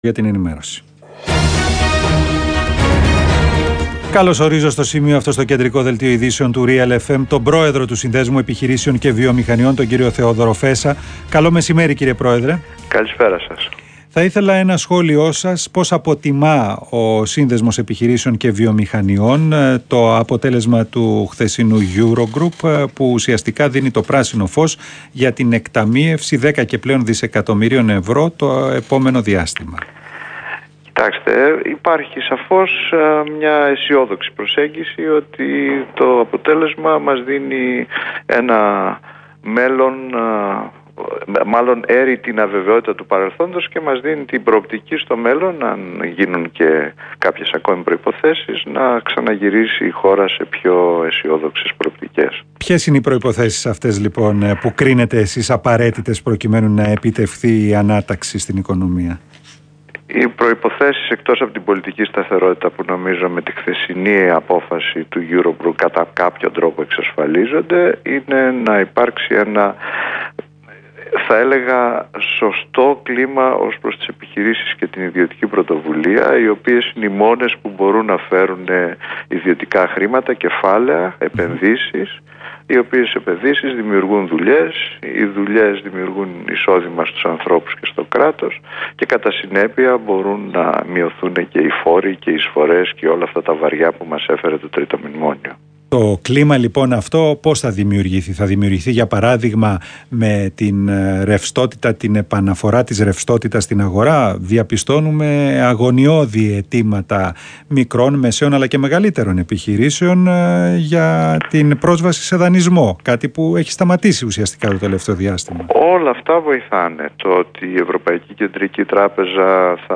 Σχόλιο
στον REAL FM